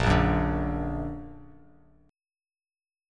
Bb+ Chord
Listen to Bb+ strummed